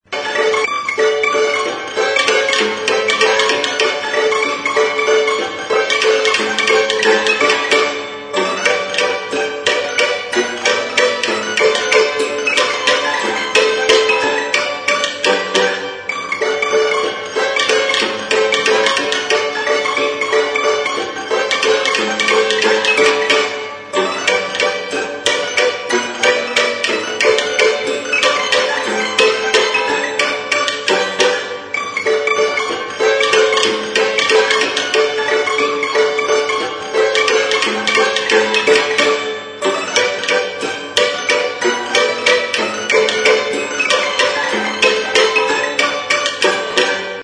Stringed -> Mechanical / pianola / piano
Registered with this music instrument.
Soken kontra jotzen duten 32 mailutxo ditu, kaxa txina eta trianguloa ditu.